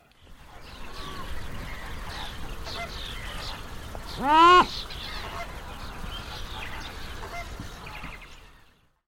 Крик коипу в водоеме